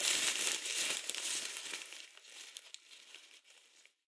torn-paper-sound_25352.mp3